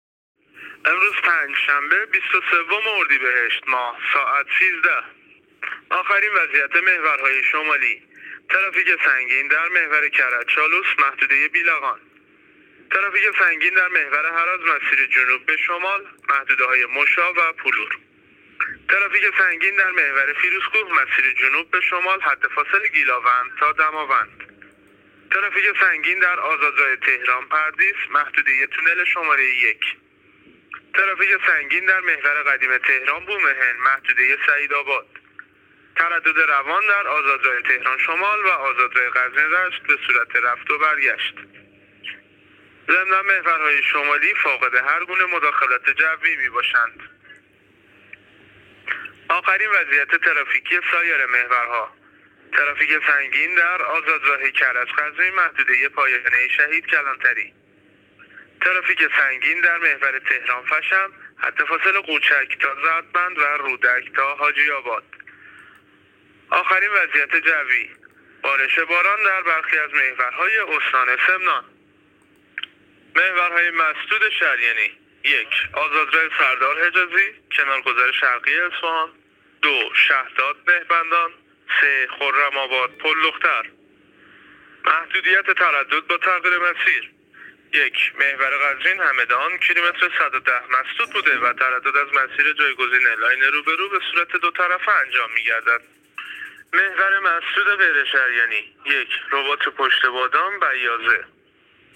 گزارش رادیو اینترنتی از آخرین وضعیت ترافیکی جاده‌ها تا ساعت ۱۳ بیست‌و سوم اردیبهشت